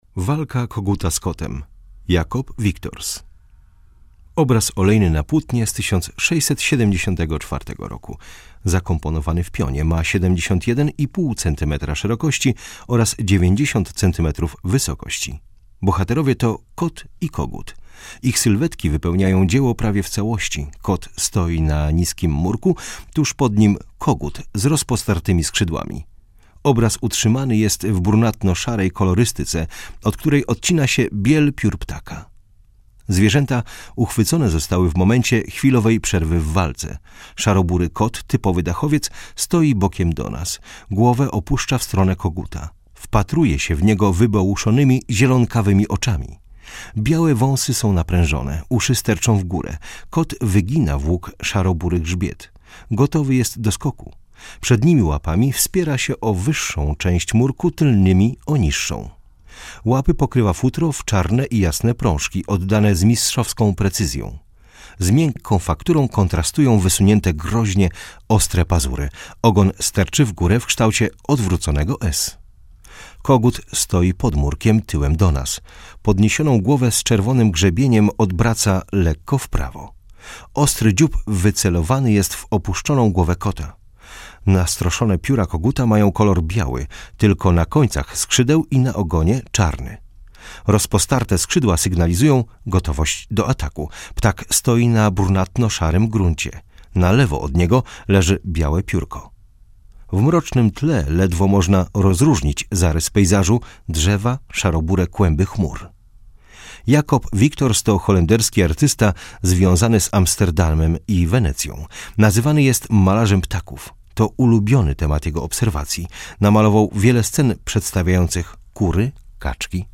Audiodeskrypcja - EUROPEUM